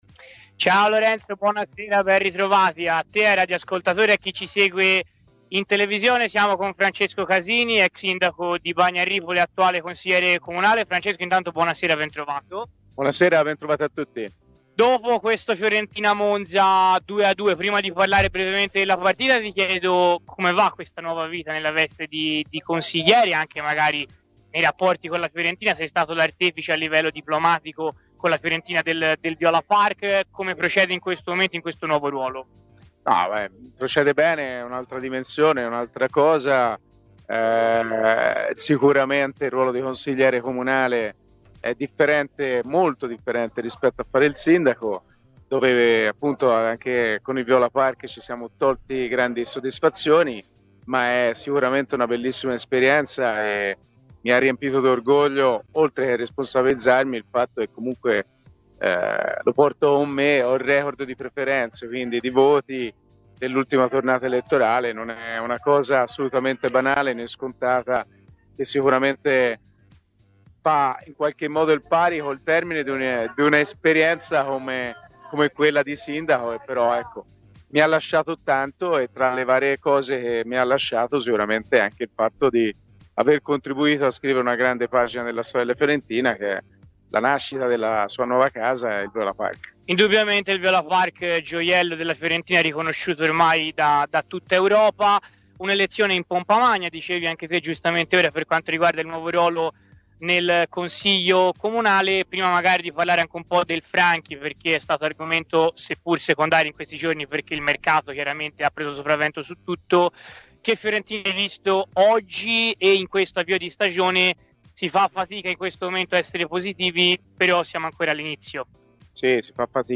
Nel post gara di Fiorentina-Monza, fuori dal Franchi, l'ex sindaco di Bagno a Ripoli - ora consigliere comunale nella giunta di Firenze - Francesco Casini ha parlato così in diretta su Radio FirenzeViola: "Ora si fa fatica ad essere positivi.